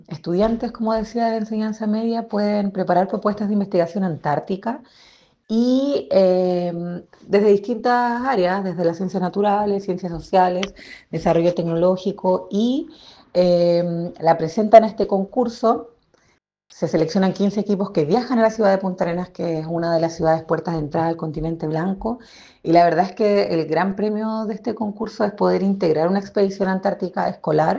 Charla del INACh